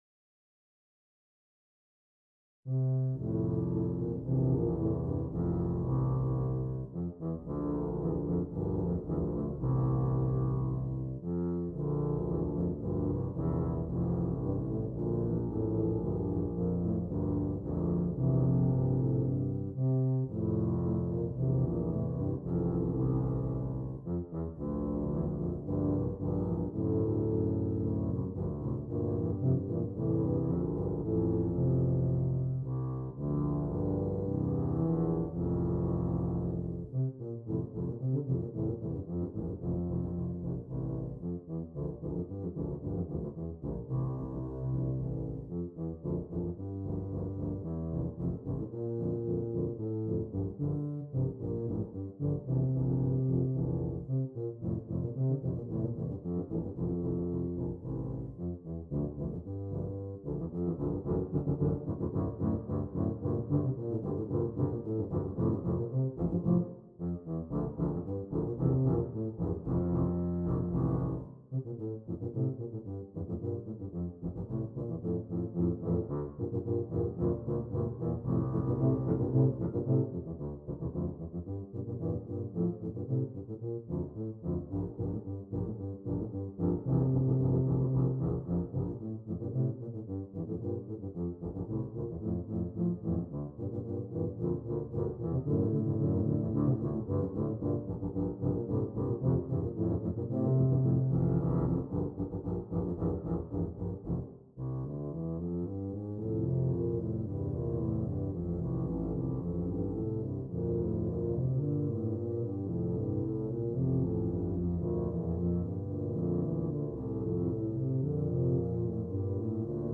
3 Tubas